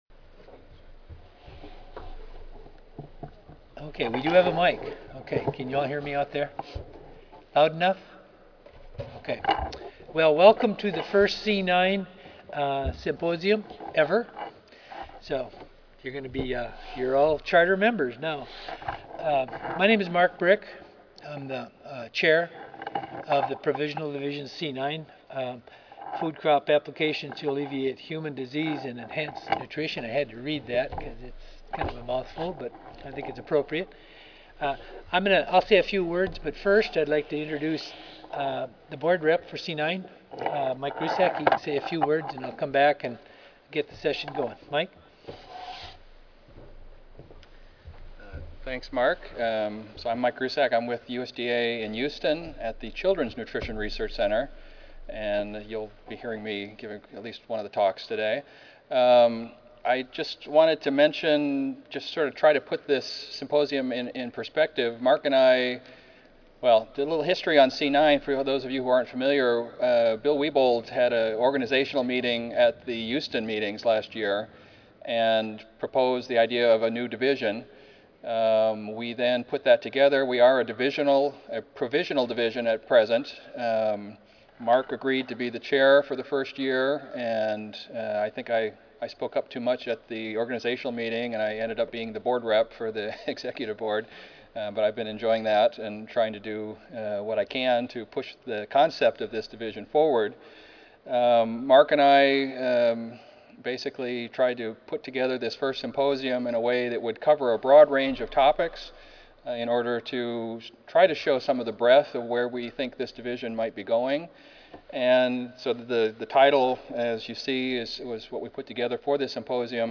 Introductory Remarks
Audio File Recorded presentation